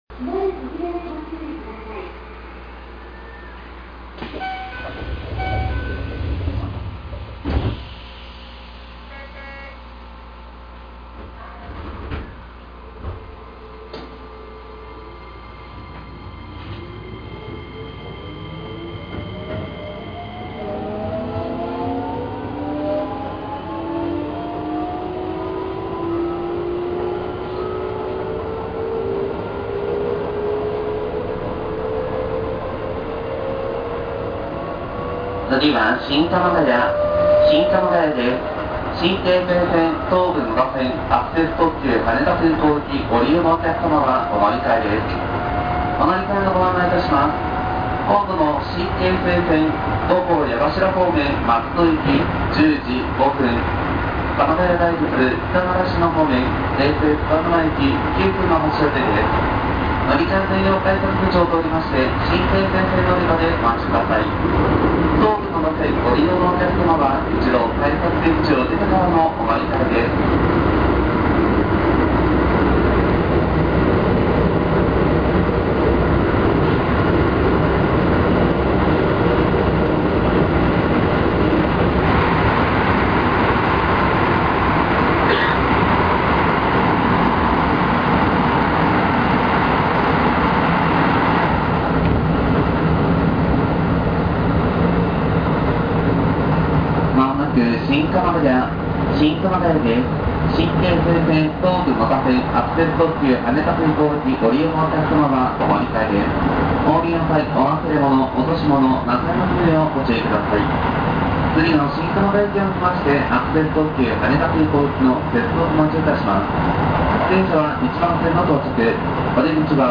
・3050形走行音
【北総線】西白井→新鎌ヶ谷（3分3秒：1.50MB）
3000形と全く同じ東洋IGBTです。高速性能に多少の差こそあれど、音だけ切り取ってしまうとほぼ変わりません。